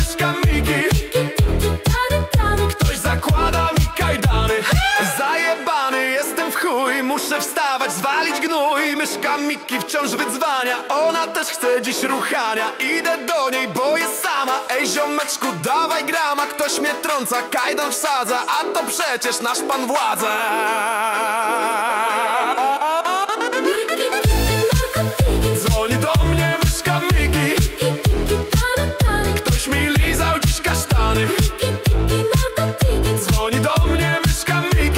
Жанр: Поп музыка / Танцевальные
Dance, Pop